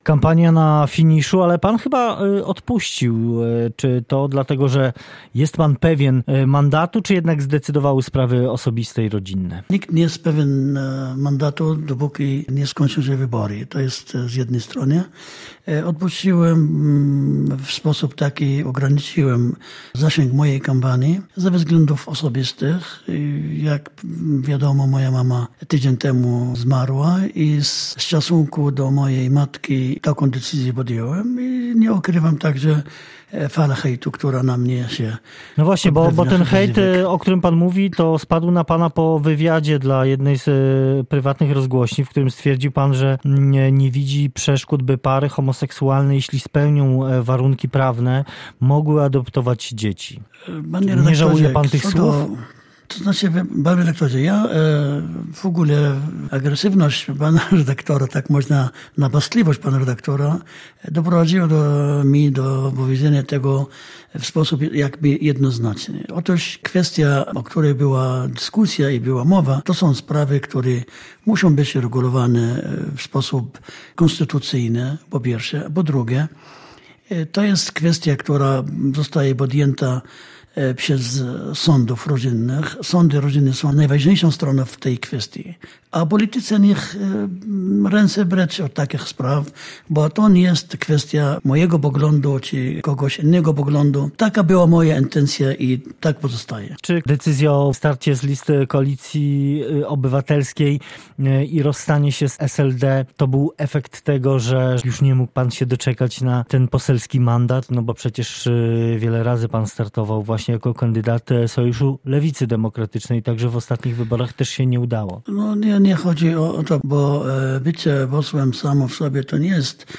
a_10_10_rozmowa_haidar.mp3